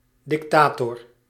Ääntäminen
IPA : /dɪkˈteɪtə(ɹ)/